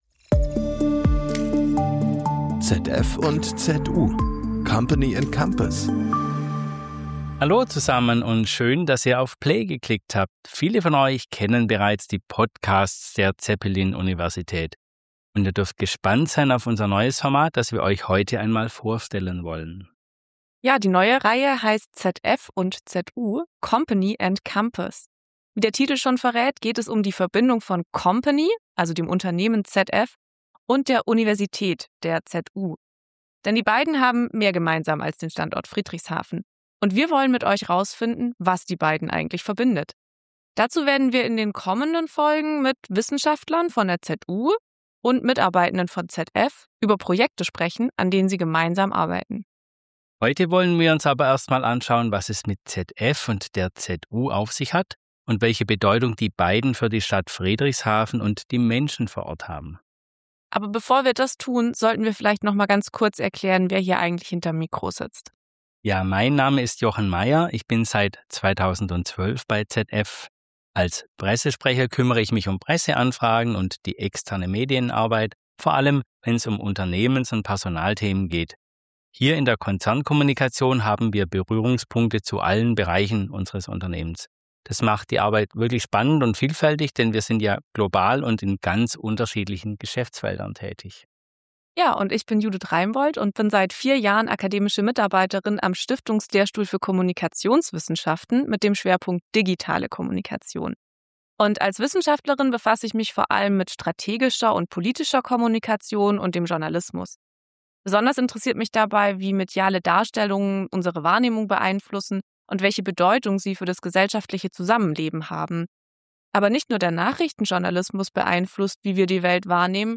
KI als Game Changer? Ein Gespräch